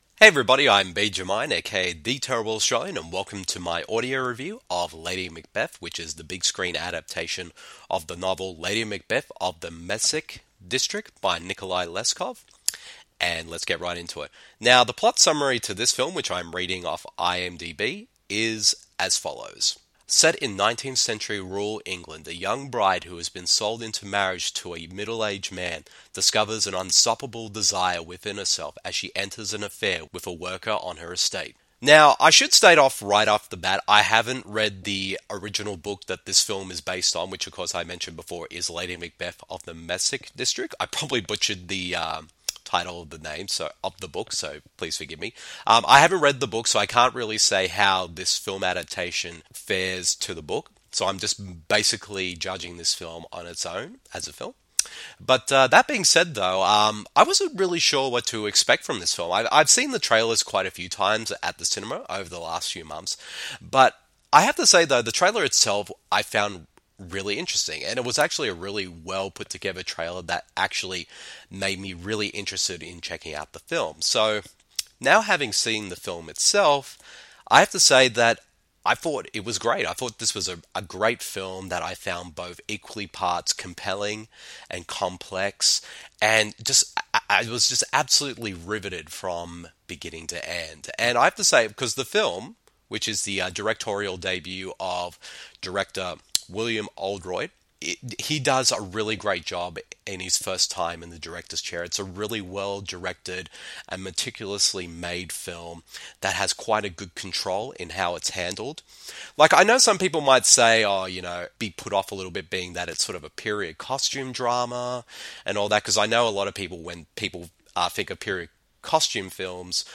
I saw the film a weeks back at early preview/media screening and I wanted to share my thoughts on it (due to both very busy work and personal life, I didn’t get a chance to until now). The following review of the film is in an audio format.